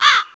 Vox
pbs - metro crow [ Vox ].wav